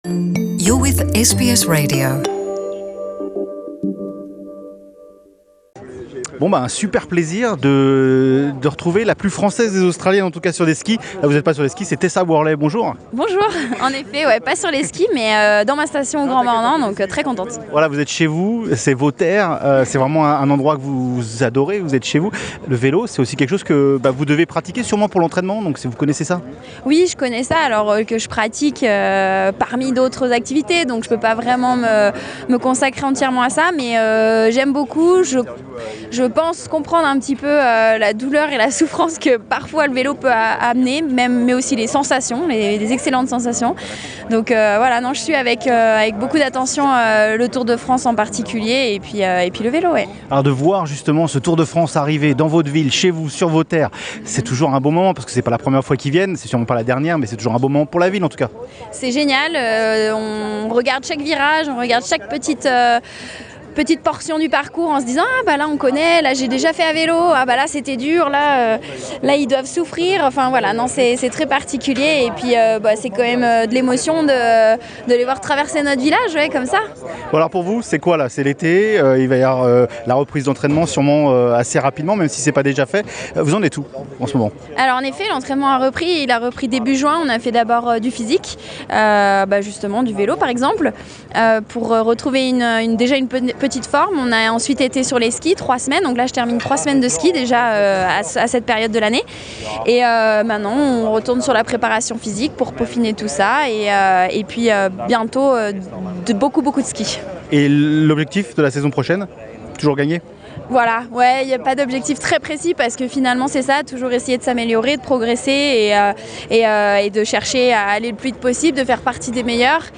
Rencontre avec Tessa Worley, la skieuse alpine française sur le Tour de France au Grand-Bornand.